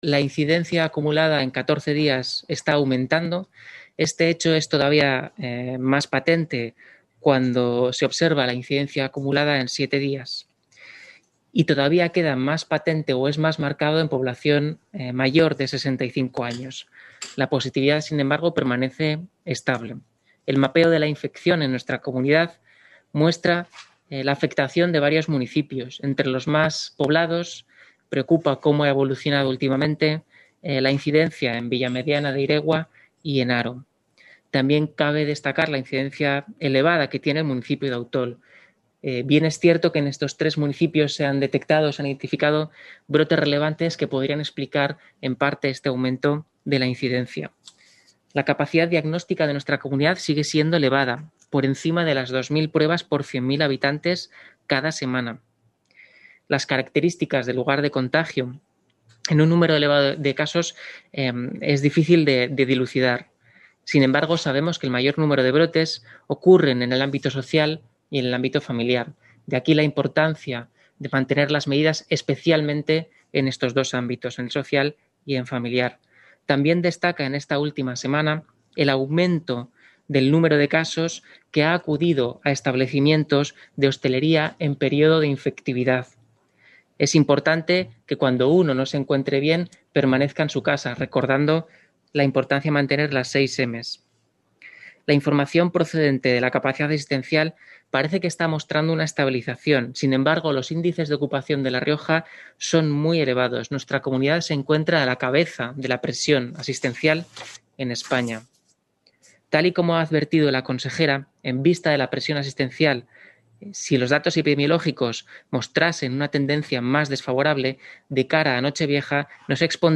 El director general de Salud Pública, Pello Latasa, ha destacado esta preocupación en el trascurso de una comparecencia de prensa para informar sobre el calendario de vacunación que se inicia este domingo y los últimos datos del coronavirus en La Rioja.